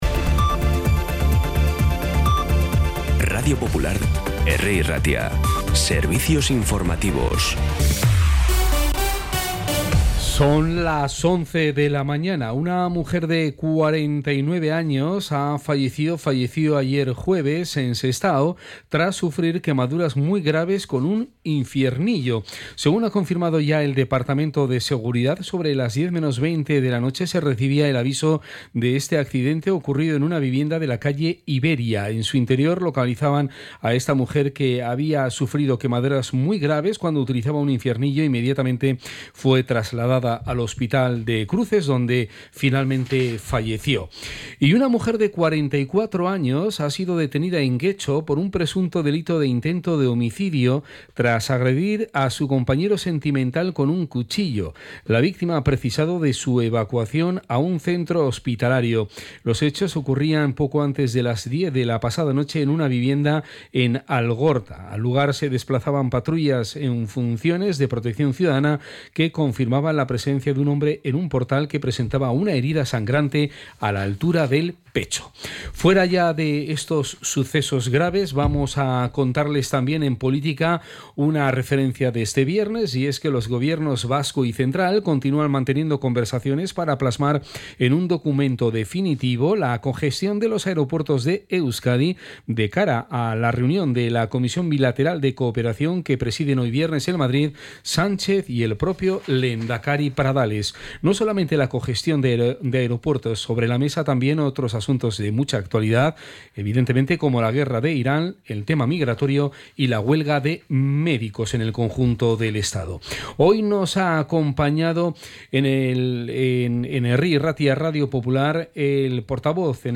Las noticias de Bilbao y Bizkaia del 27 de marzo a las 11
Los titulares actualizados con las voces del día. Bilbao, Bizkaia, comarcas, política, sociedad, cultura, sucesos, información de servicio público.